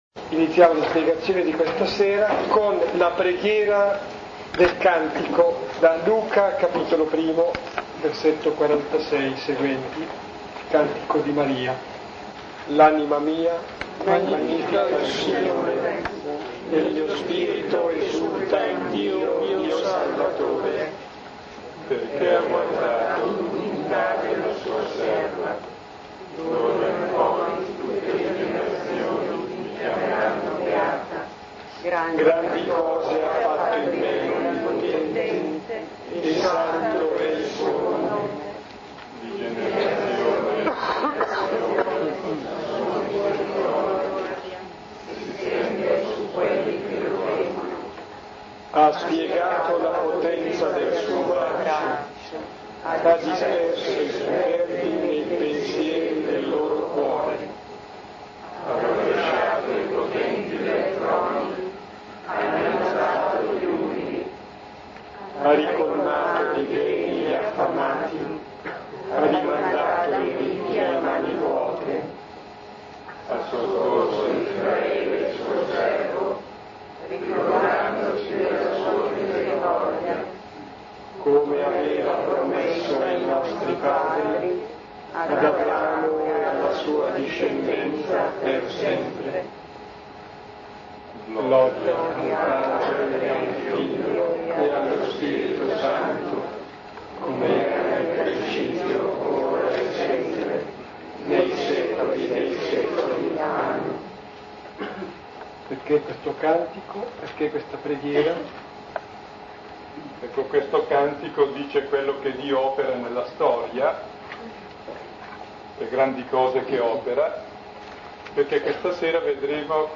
Il testo è una sintesi redazionale della lectio divina tenuta nella Chiesa di San Fedele in Milano nel corso di vari anni.